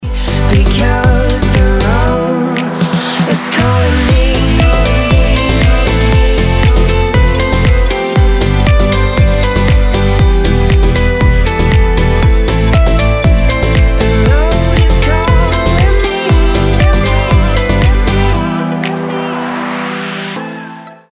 красивые
женский вокал
deep house
dance
Electronic